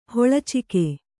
♪ hoḷacike